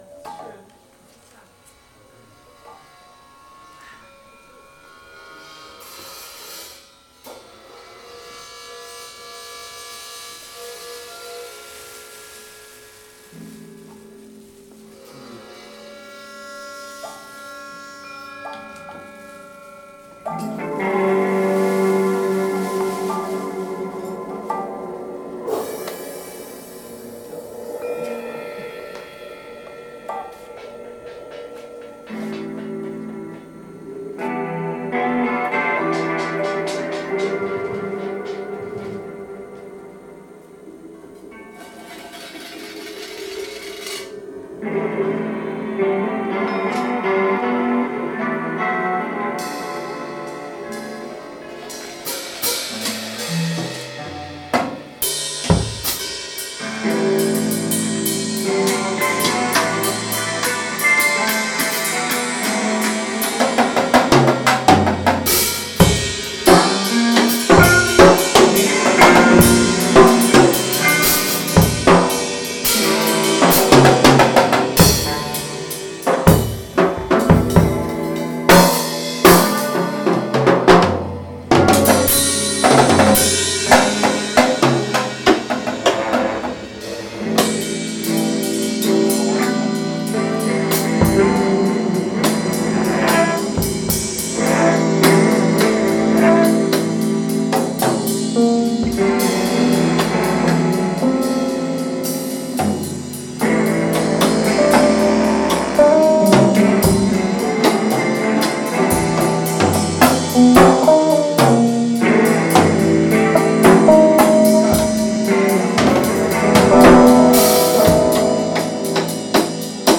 Saxophone/Bassklarinette/Percussion
E-Gitarren
Schlagzeug
Live aufgenommen bei der Soester Jazznacht 07.02.2026